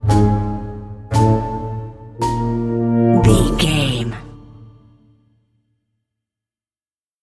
Aeolian/Minor
orchestra
percussion
strings
horns
piano
silly
circus
goofy
comical
cheerful
perky
Light hearted
quirky